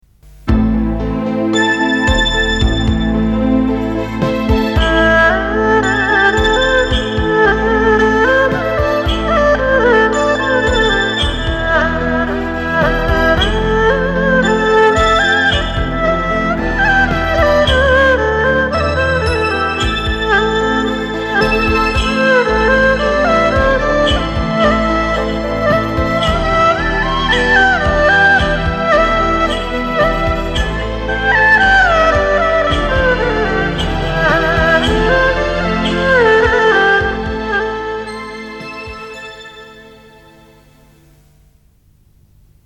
请各位老师帮我听听，是藏族风格的曲子。